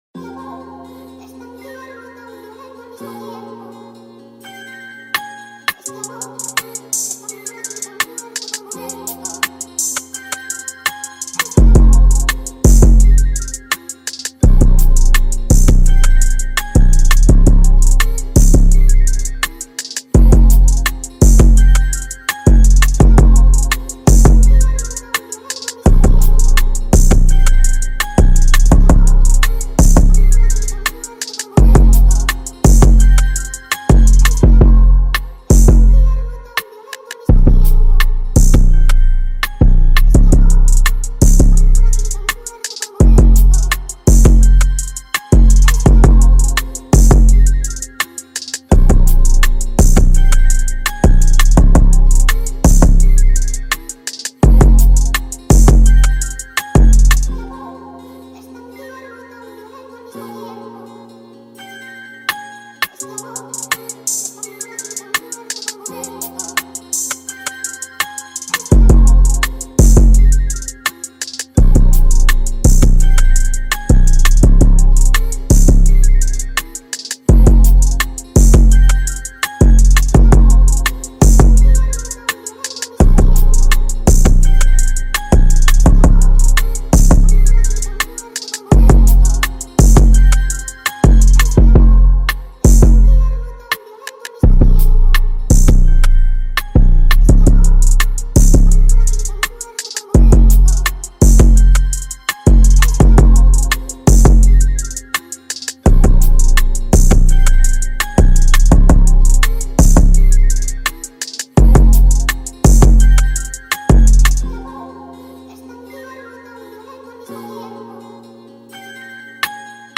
official instrumental
Trap Instrumentals